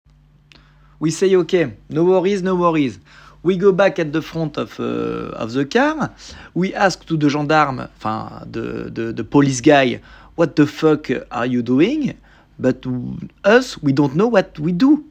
Son accent français